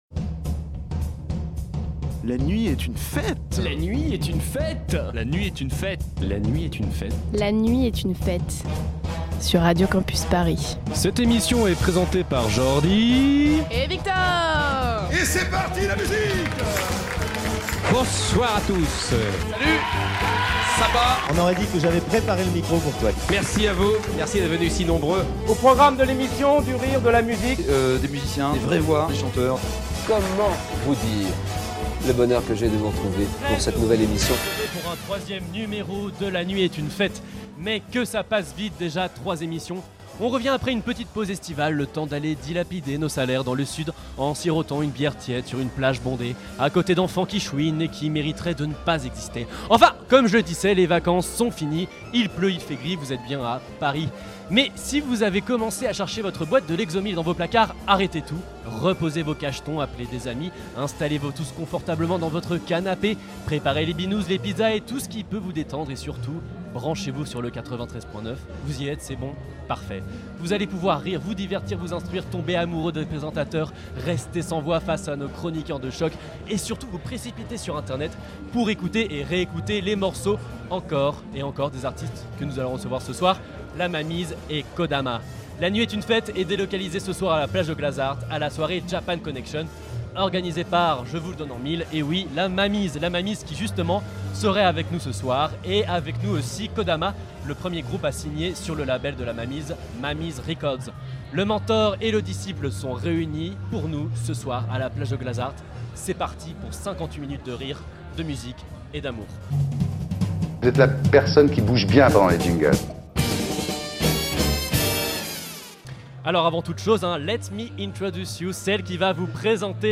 Pour sa troisième édition, la Nuit est une Fête (ou la NeF pour les pubeux que nous sommes) installe ses quartiers à la Plage de Glazart, pour la soirée organisée par le collectif La Mamie's : la Japan Connexion ! Nous découvrirons l'univers de La Mamie's, mais également la galaxie d'un groupe "mystère".